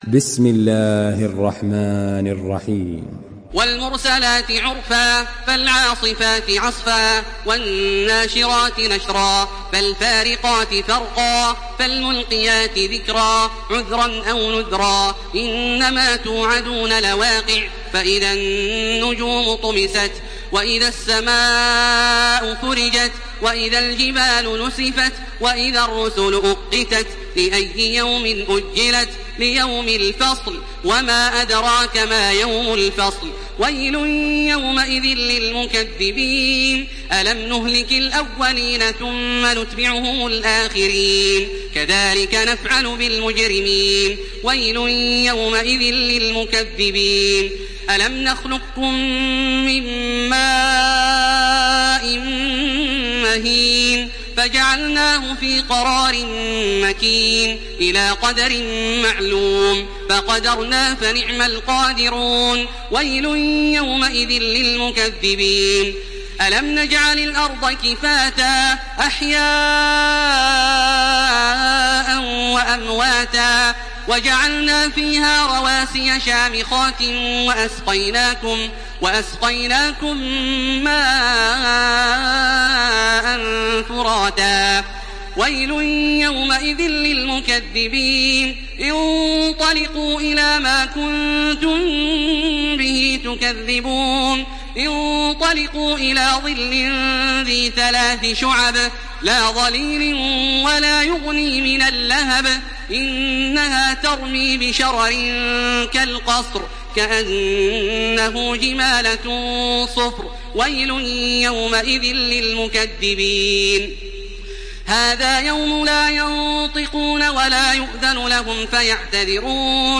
Surah Al-Mursalat MP3 in the Voice of Makkah Taraweeh 1429 in Hafs Narration
Murattal